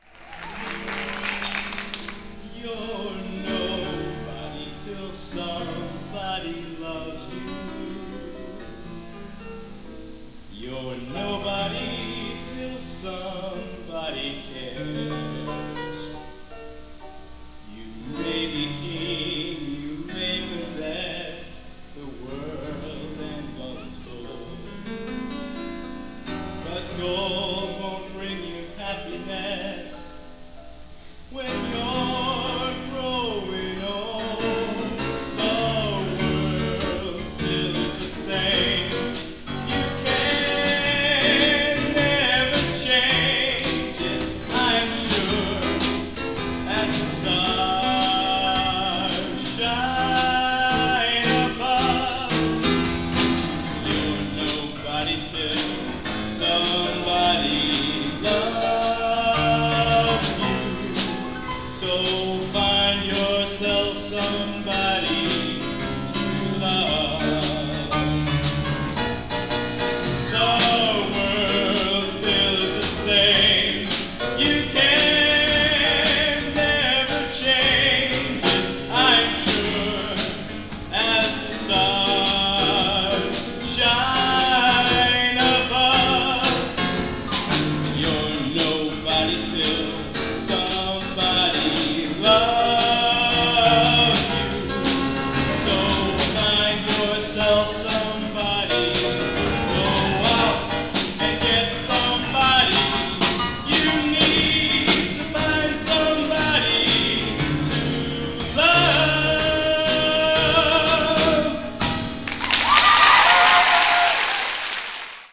Genre: | Type: Solo